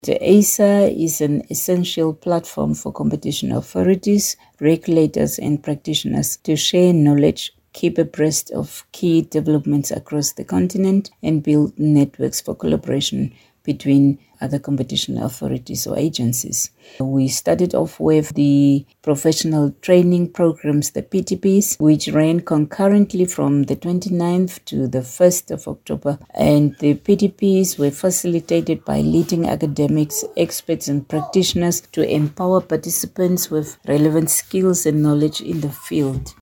Windhoek hosts 10th African Competition and Economic Regulation week - Future Media News